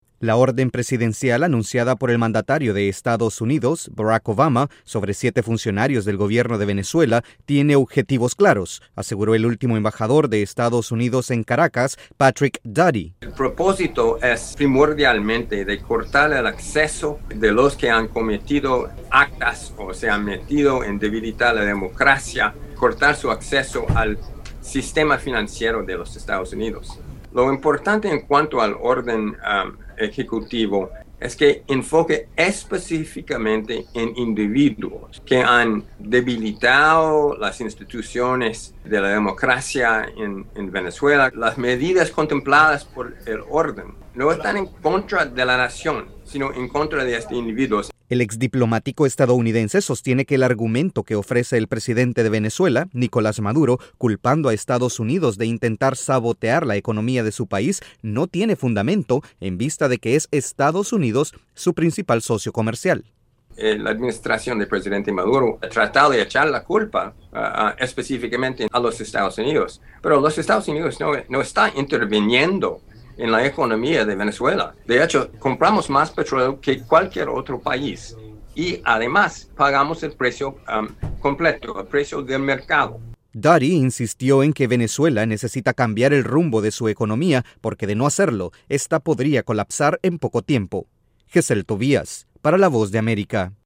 El último embajador de Estados Unidos en Venezuela, Patrick Duddy, aseguró en conversación con la Voz de América que el sistema financiero del país sudamericano está en riesgo de colapsar. Desde los estudios de la Voz de América en Washington informa